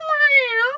cat_2_meow_08.wav